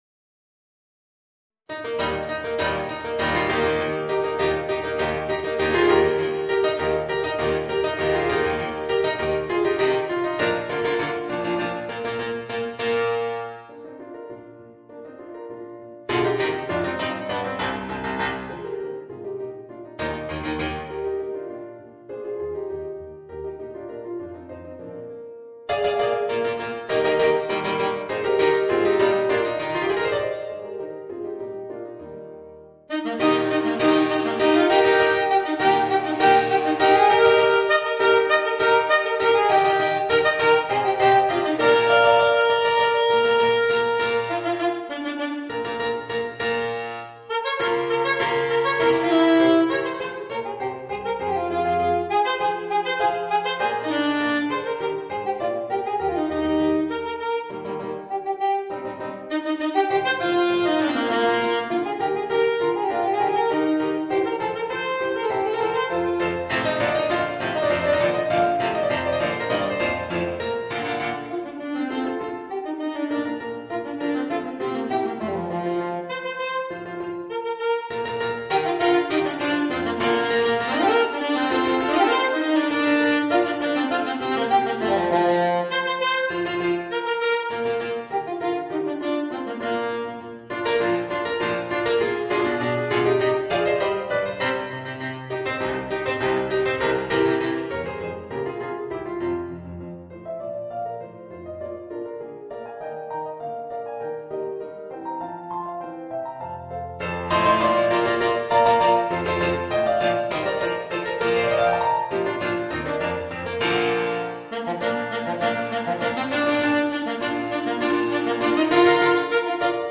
Voicing: Alto Saxophone and Organ